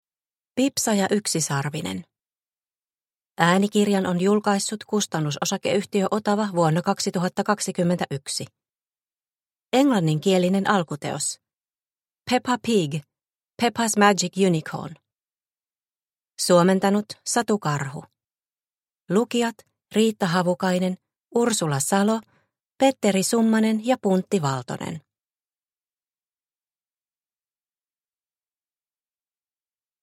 Pipsa Possu ja yksisarvinen – Ljudbok – Laddas ner